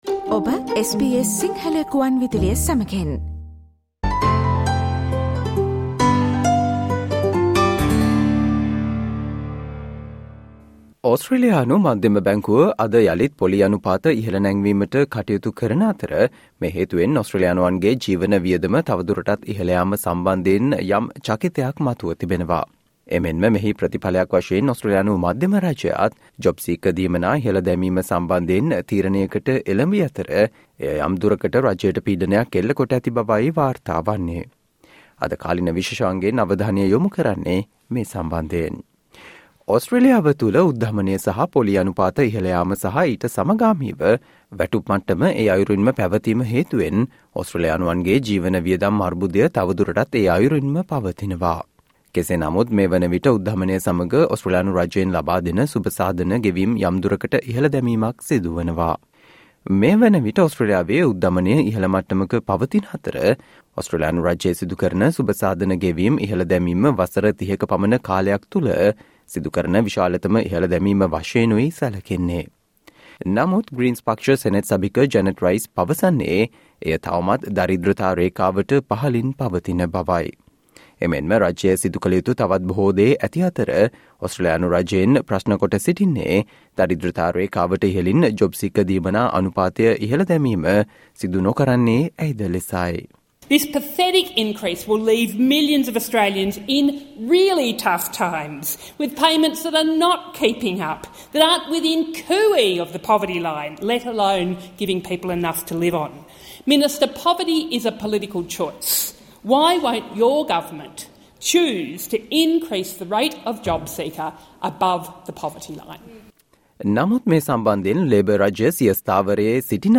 Today - 6 September, SBS Sinhala Radio current Affair Feature on The government is under intense pressure from higher increases to help offset Australia's rising cost of living